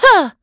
Valance-Vox_Attack2.wav